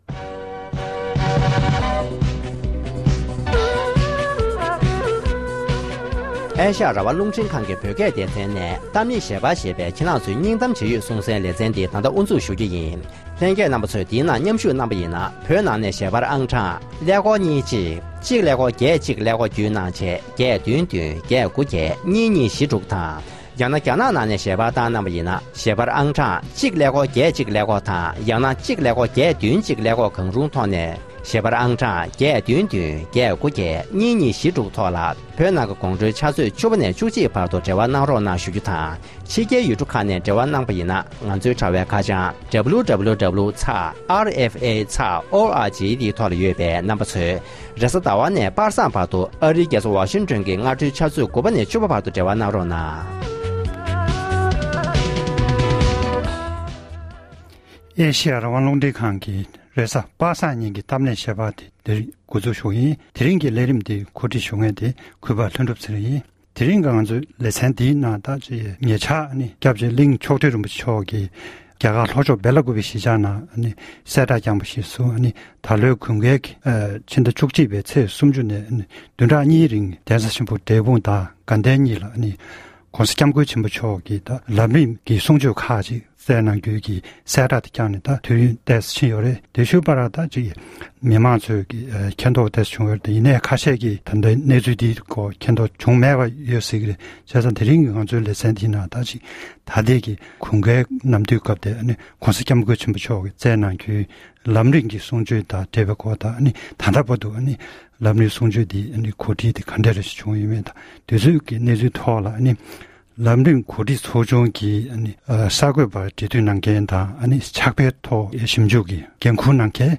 དེ་རིང་གི་གཏམ་གླེང་ཞལ་པར་གྱི་ལེ་ཚན་